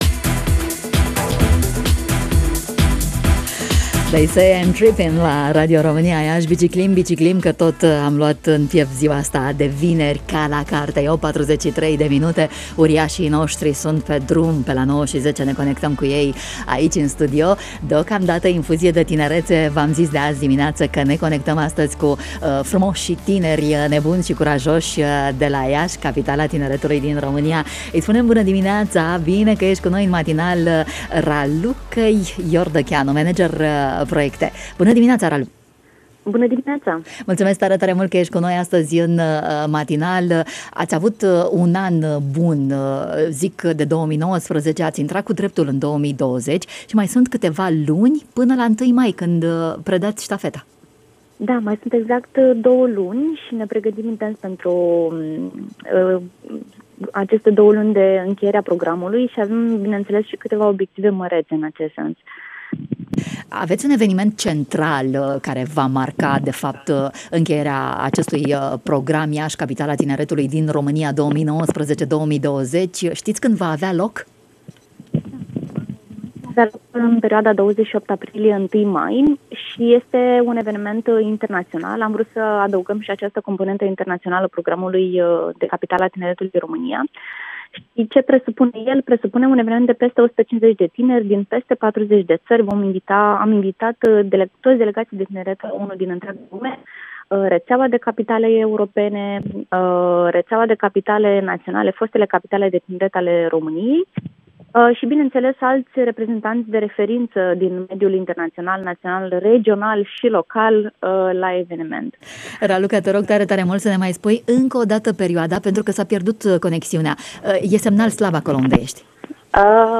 în matinalul Radio România Iaşi.